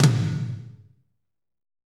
Index of /90_sSampleCDs/Northstar - Drumscapes Roland/DRM_Fast Rock/KIT_F_R Kit Wetx
TOM F RTO10L.wav